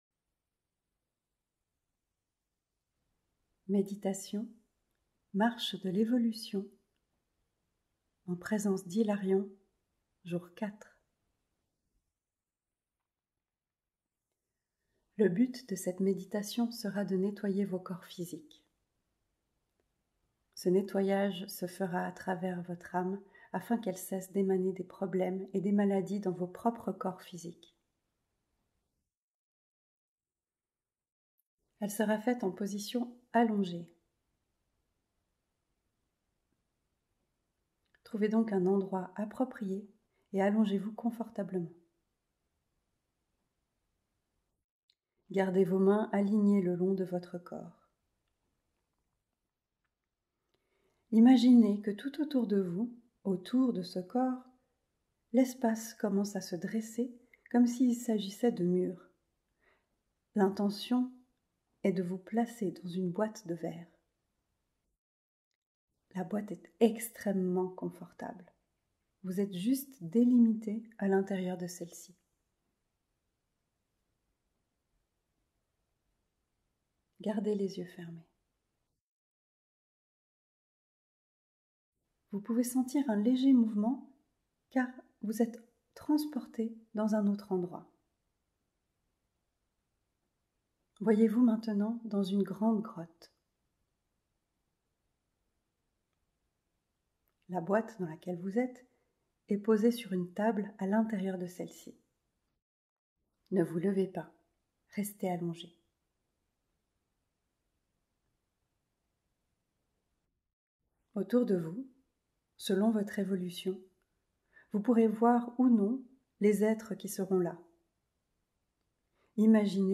Méditation - sans_pub